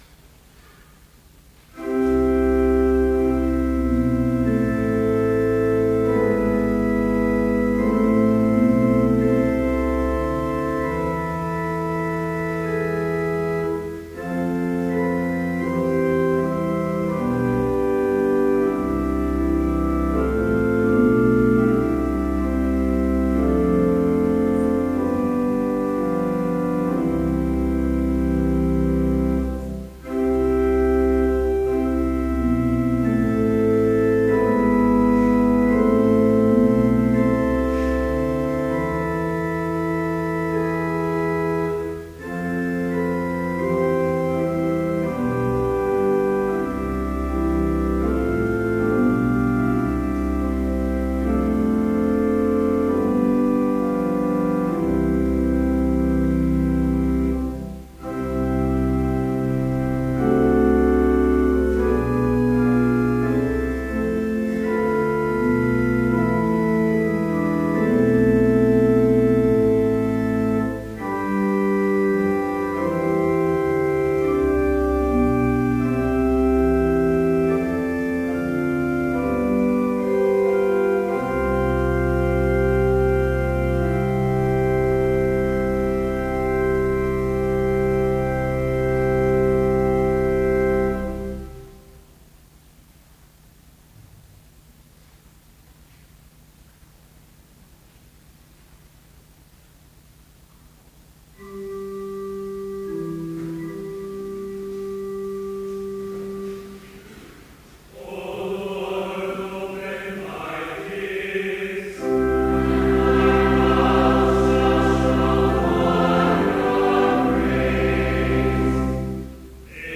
Vespers in Trinity Chapel, Bethany Lutheran College, on November 6, 2013, (audio available) with None Specified preaching.
Complete service audio for Evening Vespers - November 6, 2013
led by the choir